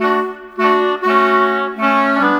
Rock-Pop 10 Winds 03.wav